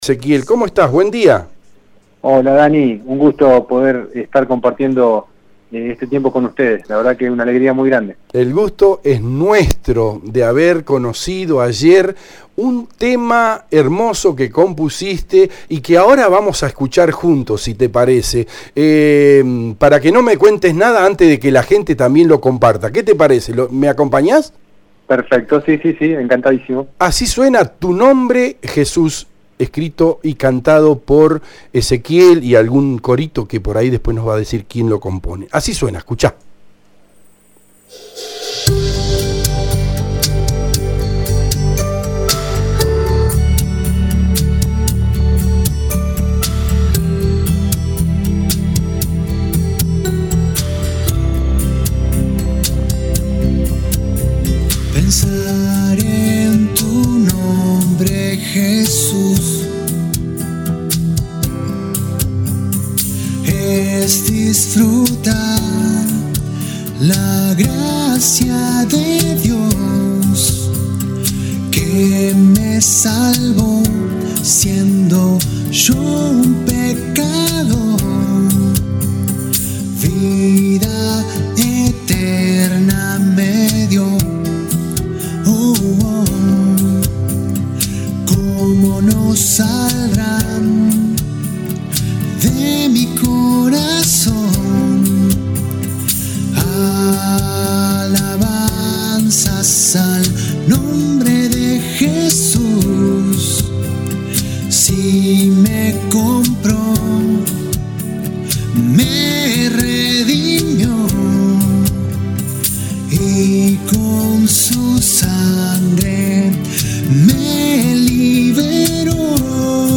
Al finalizar la charla en el programa Con Zeta de radio EL DEBATE, escuchamos con los oyentes el tema TU NOMBRE JESÚS que se estrenó horas antes y que podes ver al pie de esta nota.